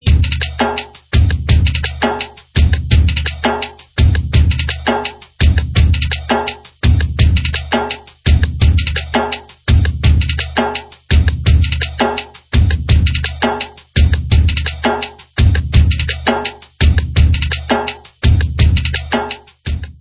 Rhythm Pattern 1